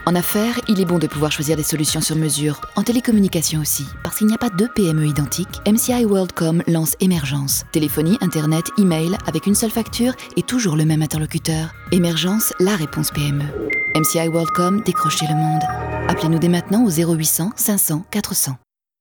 droite